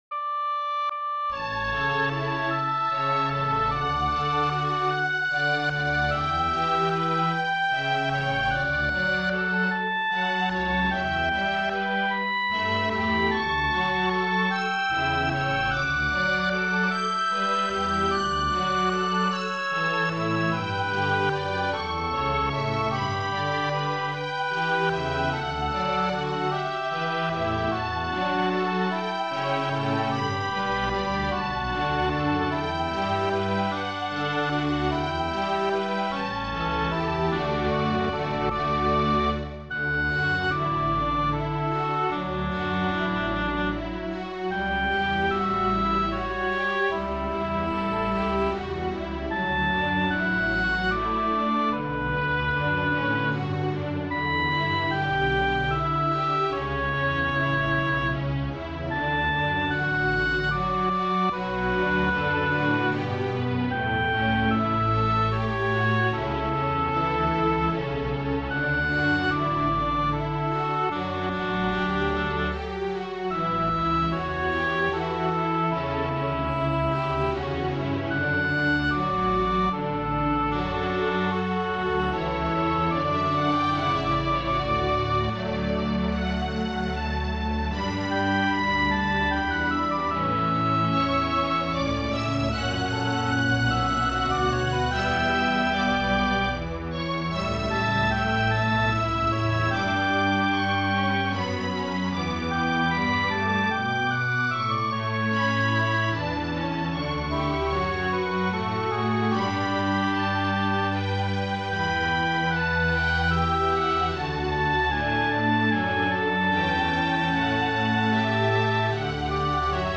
fue interpretada por la Orquesta de Cámara de la Universidad de Panamá